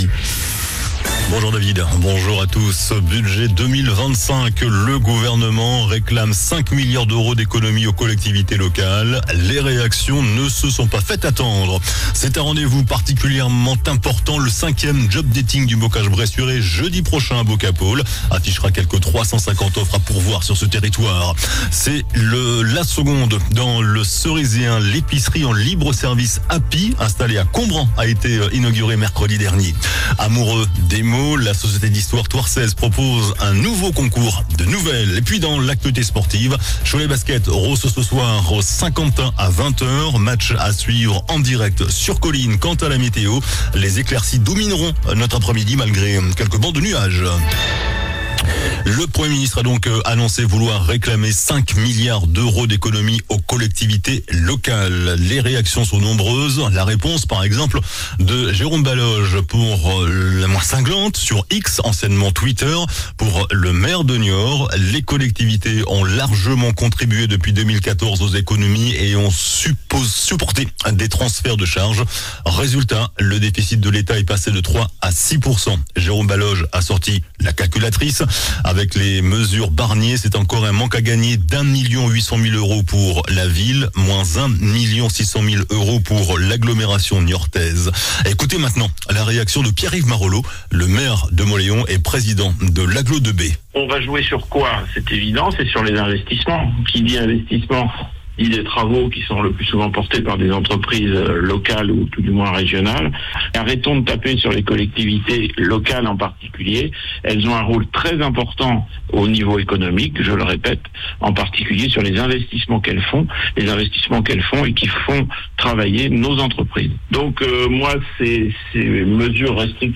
JOURNAL DU VENDREDI 11 OCTOBRE ( MIDI )